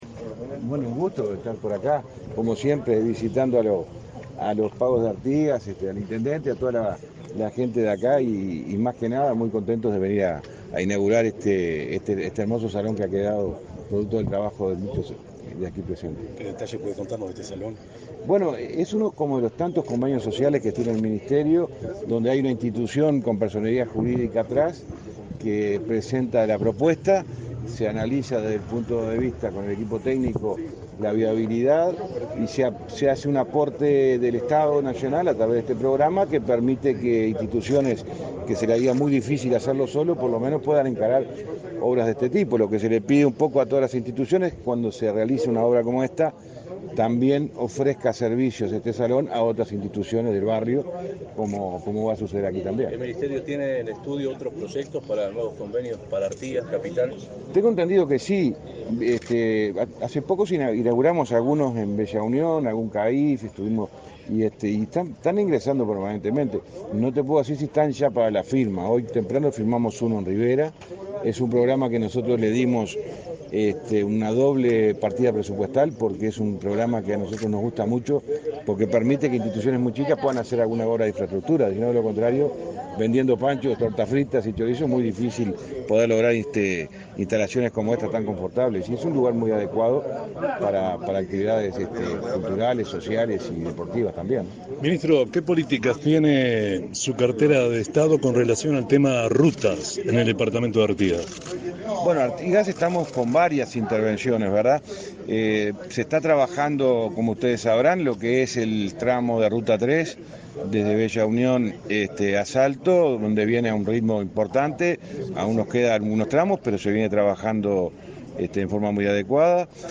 Declaraciones a la prensa del ministro de Transporte y Obras Públicas, José Luis Falero, en Artigas
Declaraciones a la prensa del ministro de Transporte y Obras Públicas, José Luis Falero, en Artigas 10/11/2022 Compartir Facebook X Copiar enlace WhatsApp LinkedIn El ministro de Transporte y Obras Públicas, José Luis Falero, participó en la inauguración de obras edilicias del Club Atlético Independencia, de Artigas, este 10 de noviembre. Tras el evento, realizó declaraciones a la prensa.